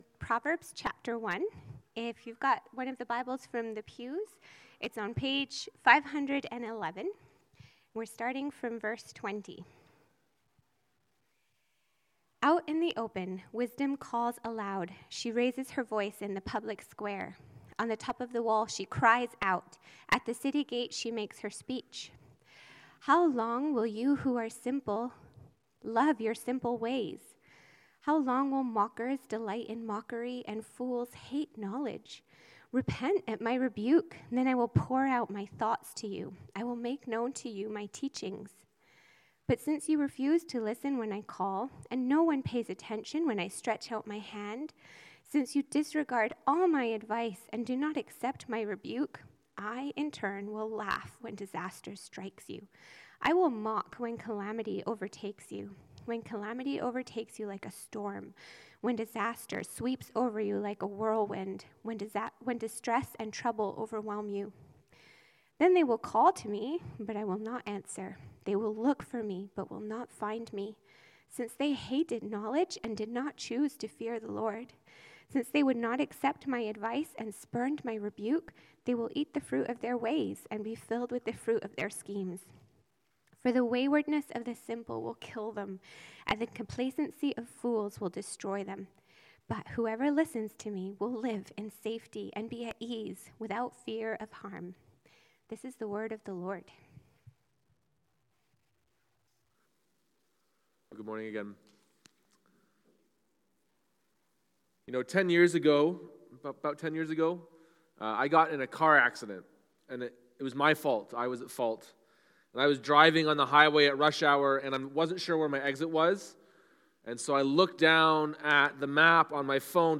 Sermons - Forward Baptist Church, Toronto